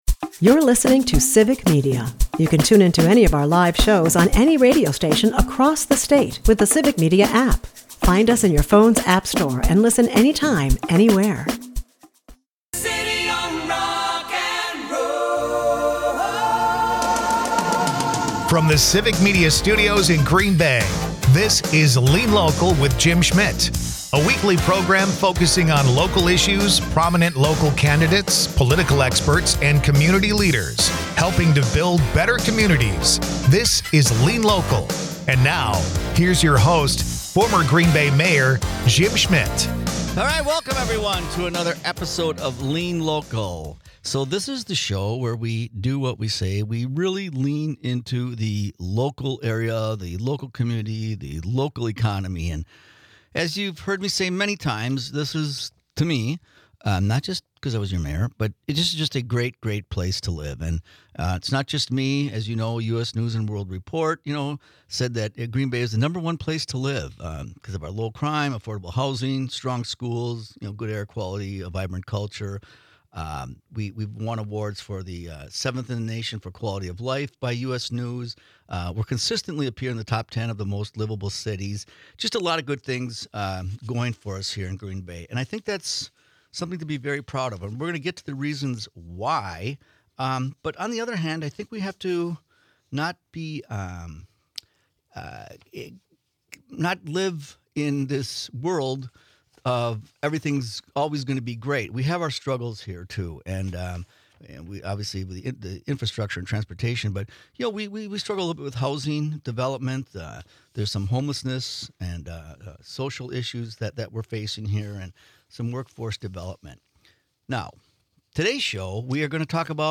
The conversation also highlights the effectiveness of collaboration with local schools to improve student readiness and the significant financial support provided to help students avoid student debt. Lean Local is a part of the Civic Media radio network and airs Sunday's from 1-2 PM on WGBW .